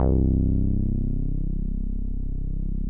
MOOG #2  C2.wav